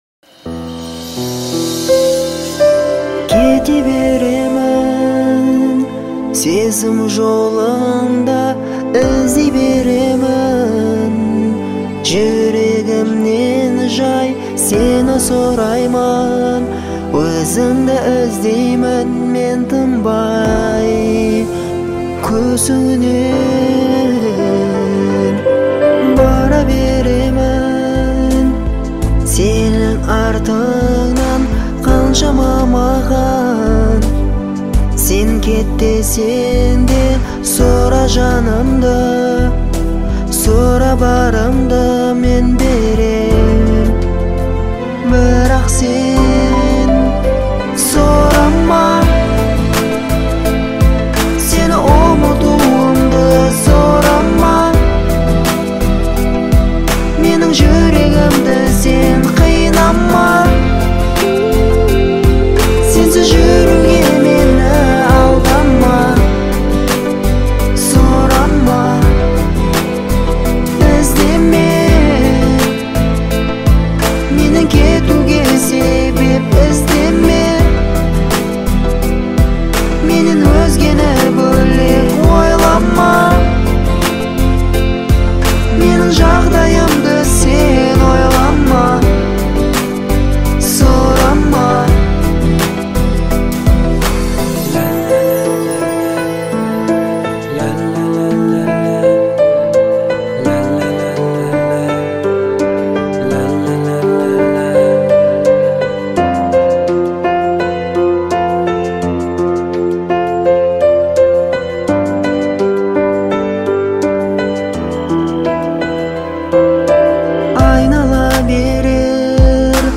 относится к жанру казахской поп-музыки.